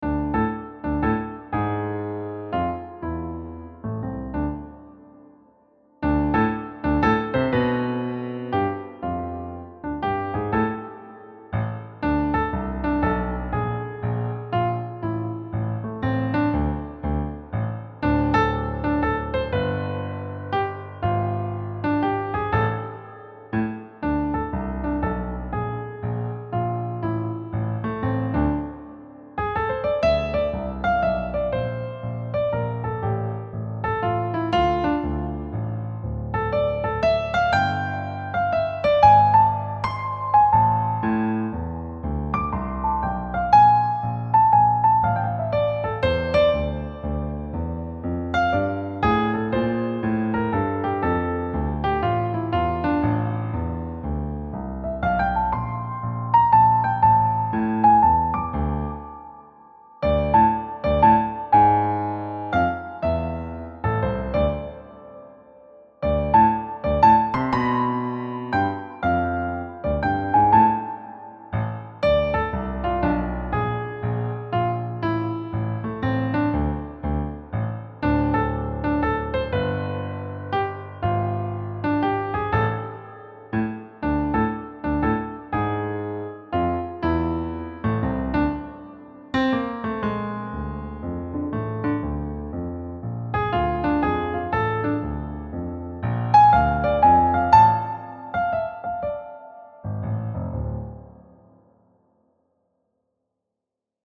Key: D harmonic minor
Time signature: 3/4 (Jazz Waltz), BPM ≈ 120
• Developing an authentic jazz swing waltz feel
• Syncopated rhythms and placement of accents